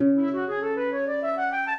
flute-harp
minuet5-6.wav